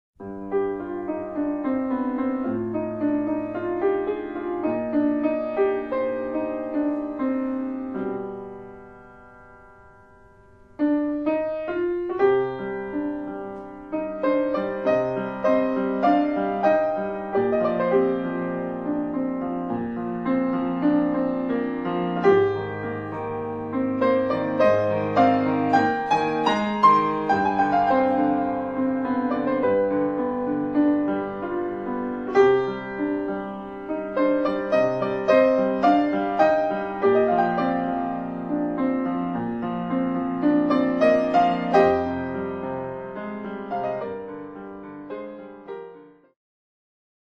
音楽ファイルは WMA 32 Kbps モノラルです。
Piano